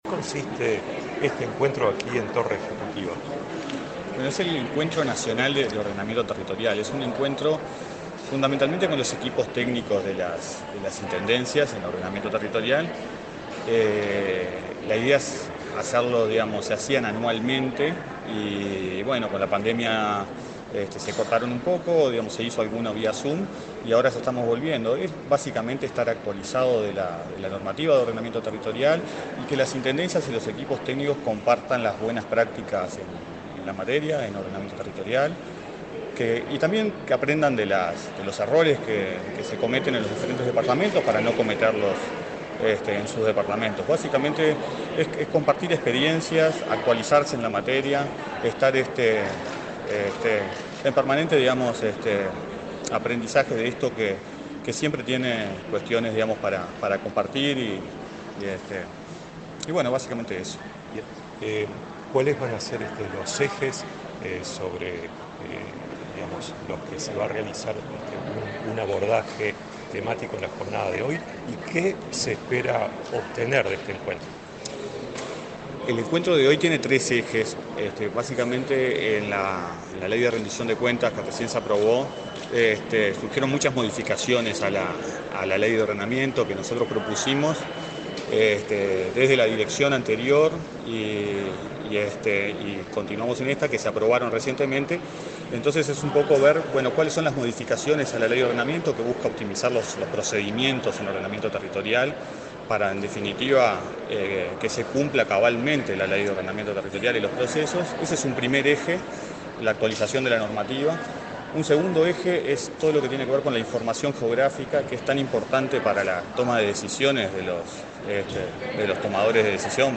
Entrevista al director nacional de Ordenamiento Territorial, José Pedro Aranco